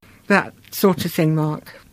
Final vocatives are normally not accented. They usually form part of the tail of the IP.